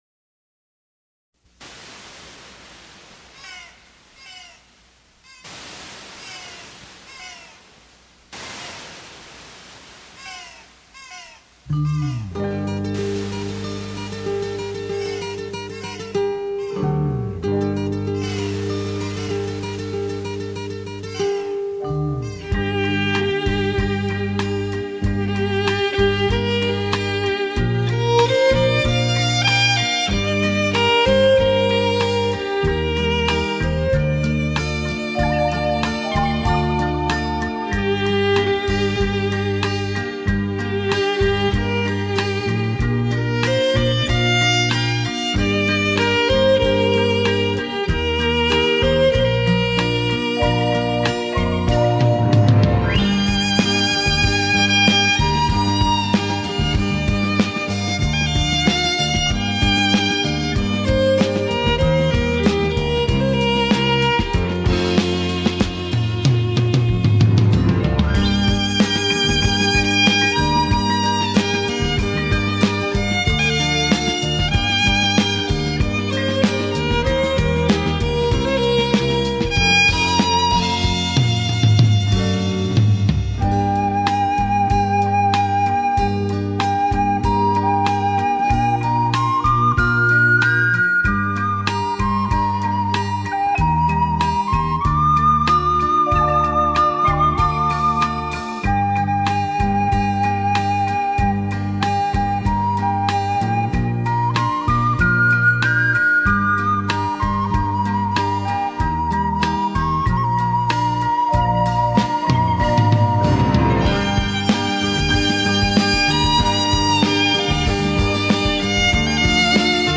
小提琴演奏